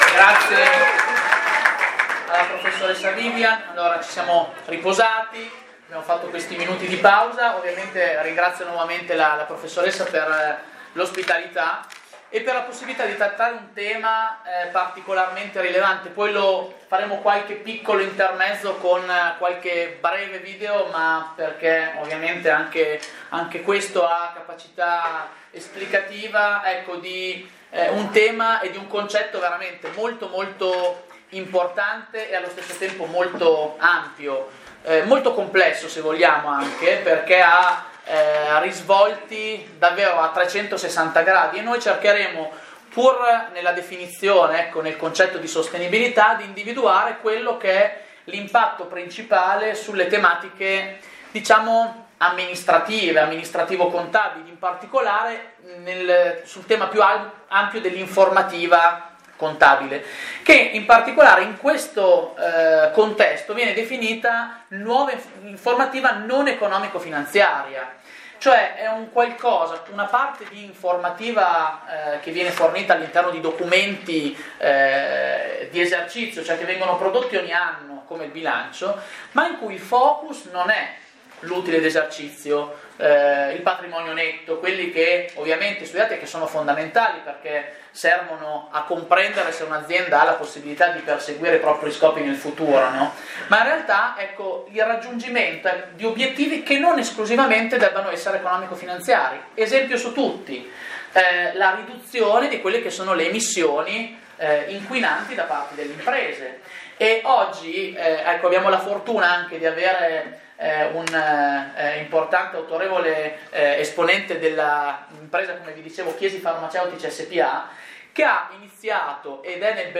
Sostenibilità e “nuova” informativa non economico-finanziaria – Lezione